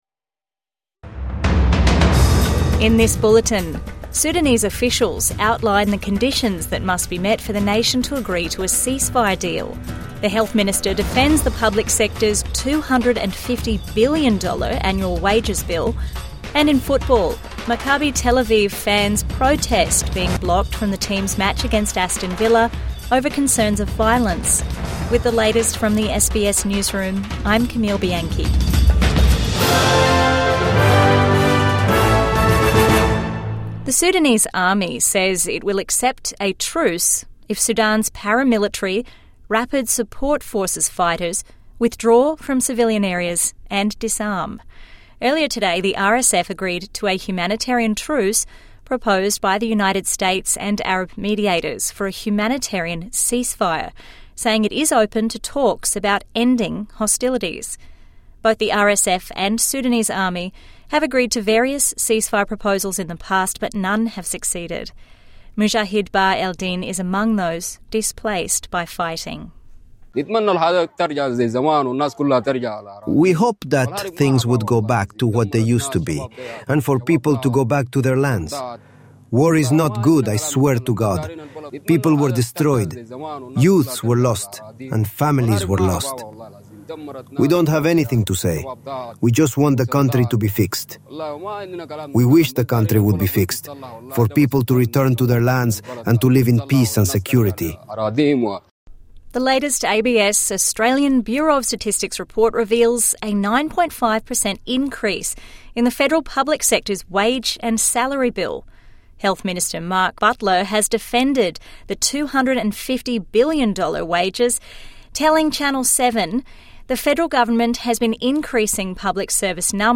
Sudan officials outline conditions to agree to peace truce | Midday News Bulletin 7 November 2025